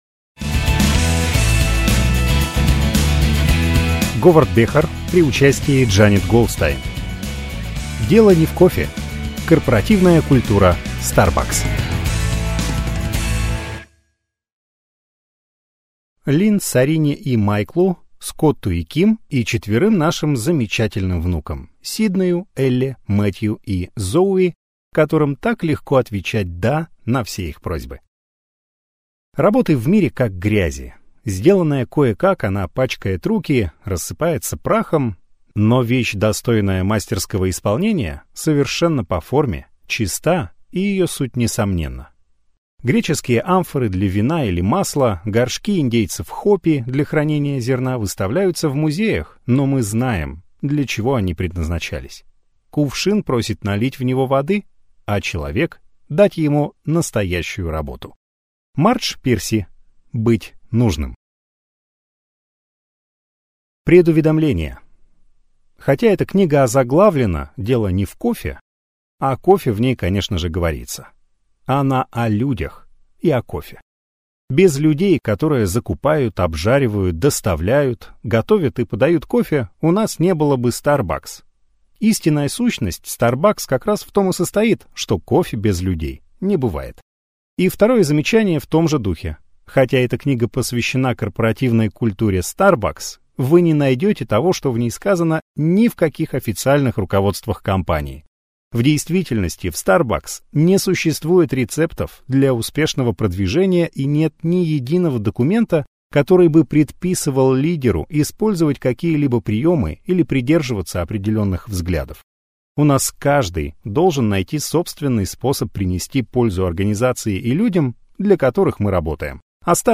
Аудиокнига Дело не в кофе: Корпоративная культура Starbucks | Библиотека аудиокниг